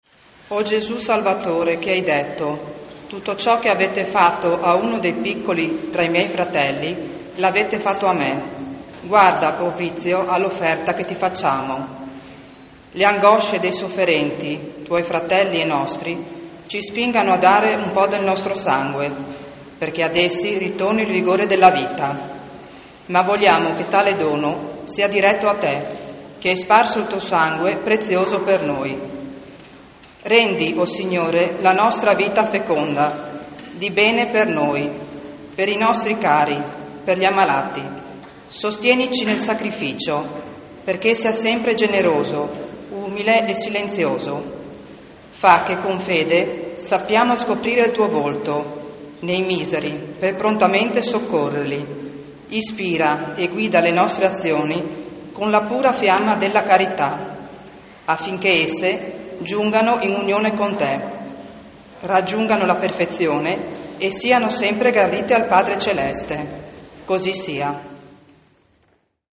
Celebrazioni per il 55° della locale Sezione Afds
CANTO E PREGHIERE DI APERTURA